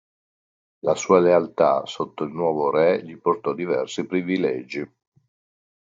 le‧al‧tà
/le.alˈta/